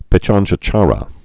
(pĭchänchə-chärə) also Pi·tjan·tjar·a (pĭchänchə-rə)